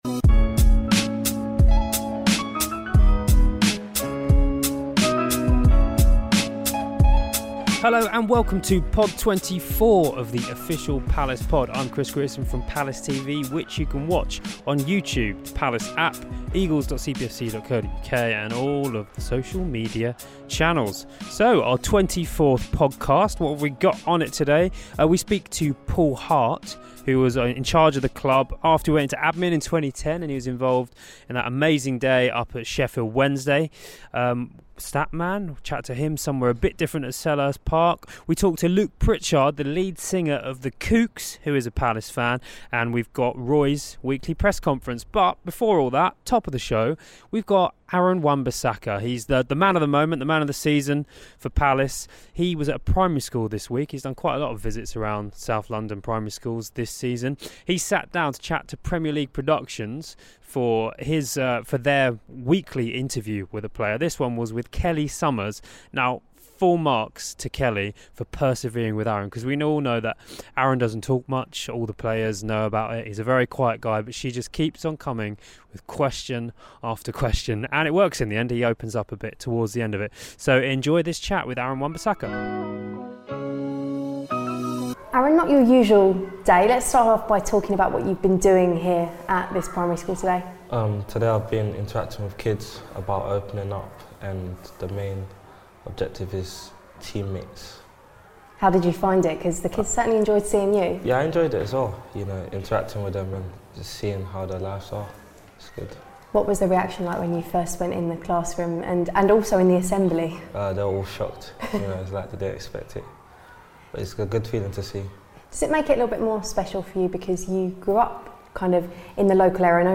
They're followed by Survival Sunday boss Paul Hart, as the former Palace manager discusses that remarkable day at Hillsborough when the Eagles were saved from relegation by the boot of a certain Darren Ambrose. Penultimately, The Kooks' frontman Luke Pritchard spoke with the Palace programme earlier in the week to reveal his affiliation with the Eagles, his thoughts on the south London music scene and about life growing up in Croydon. Rounding things up afterwards is, of course, Roy Hodgson, whose pre-Brighton press conference concludes this week's Palace Pod.